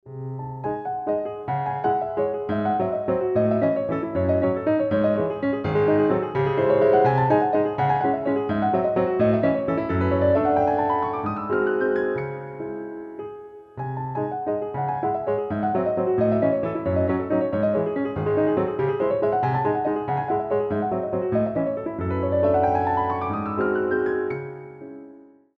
инструментальные , классические , вальс
без слов , пианино